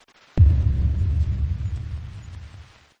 Tesla Lock Sound Boom
Boom sound
(This is a lofi preview version. The downloadable version will be in full quality)
JM_Tesla_Lock-Sound_Boom_Watermark.mp3